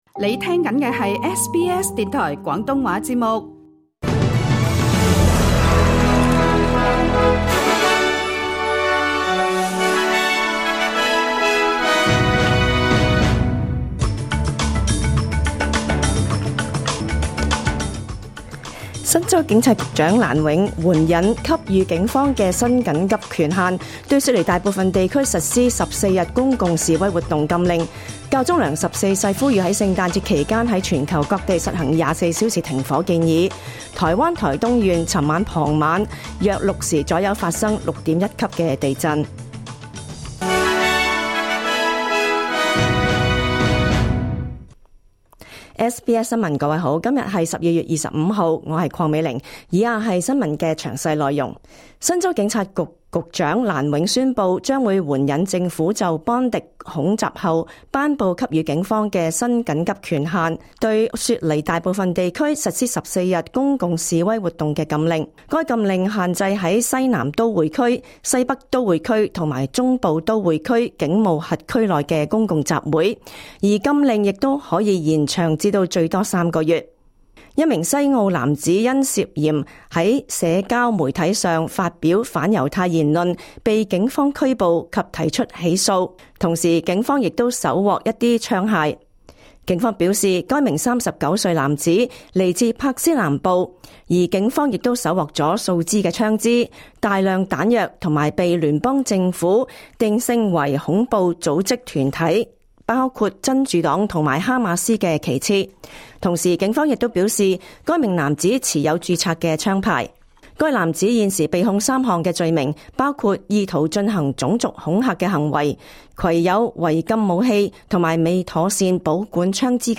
2025 年 12 月 25 日 SBS 廣東話節目詳盡早晨新聞報道。